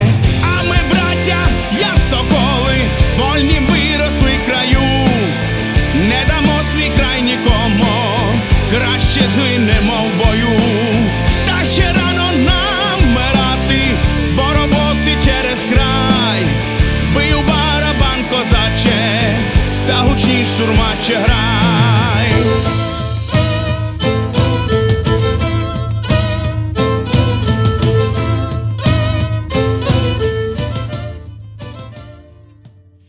• Качество: 320, Stereo
мотивирующие
Folk Rock
украинский рок
поп-рок
воодушевляющие